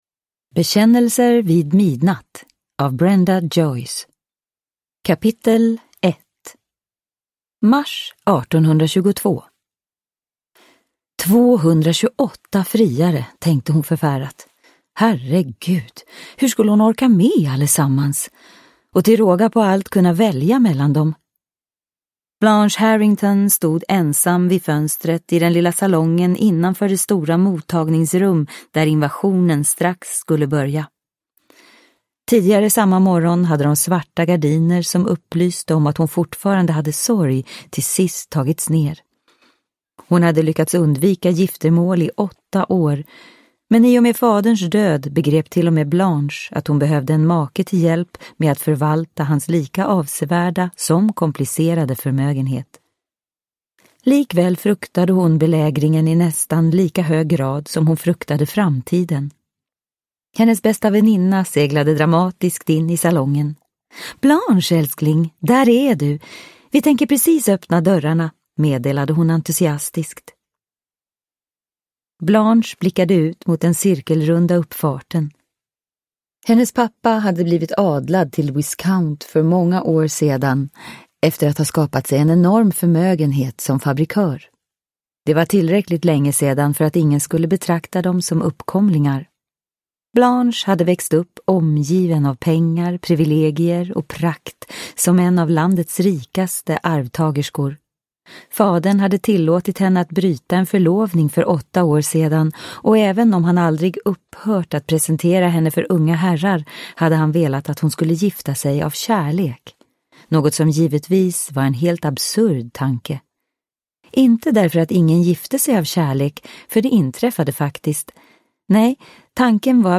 Bekännelser vid midnatt – Ljudbok – Laddas ner